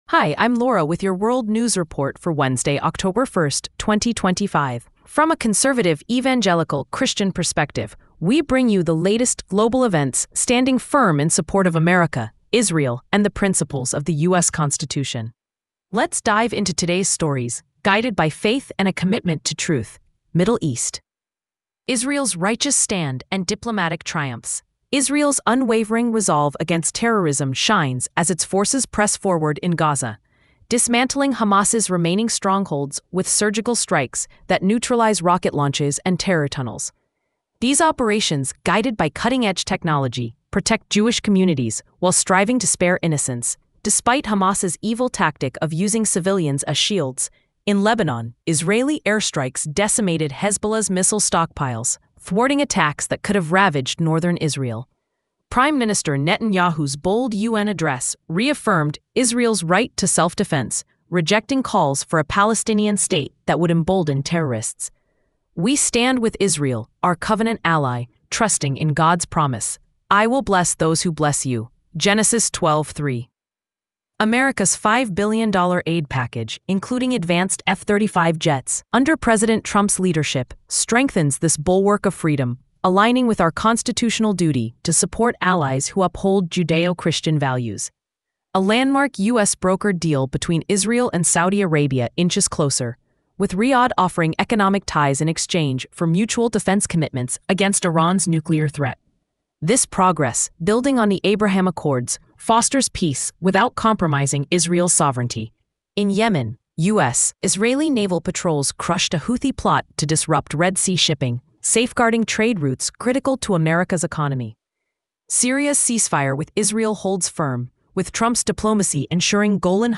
World News Report for Wednesday October 1 2025